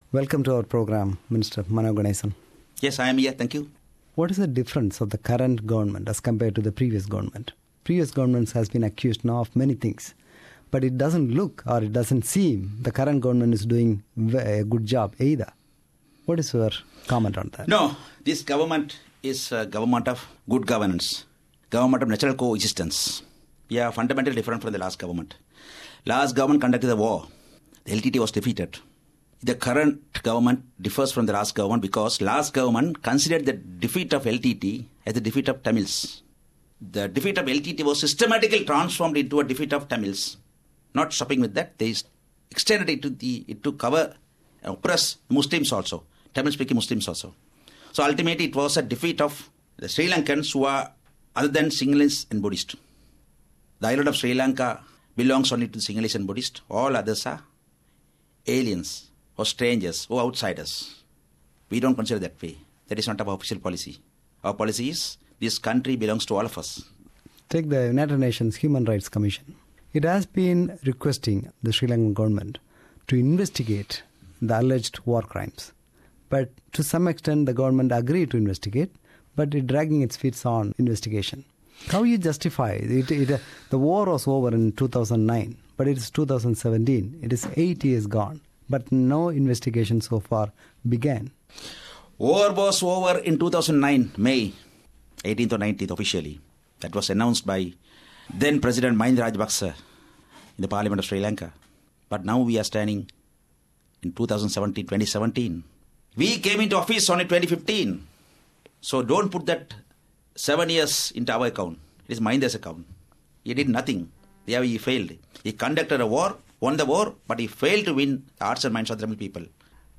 Interview with Minister Mano Ganesan